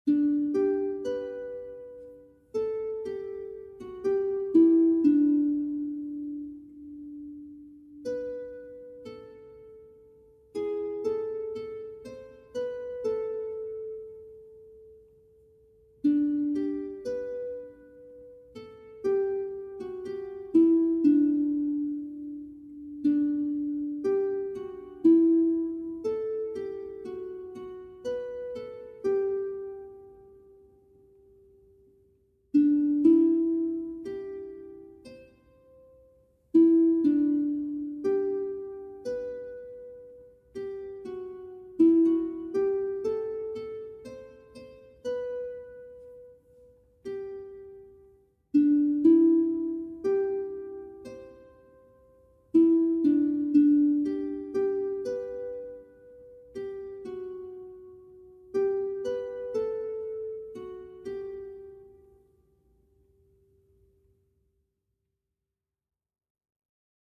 Featured Harp Music
MP3 Audio of Tune Alone